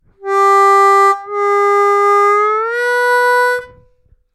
плавно в обратную сторону +3 -3'''-3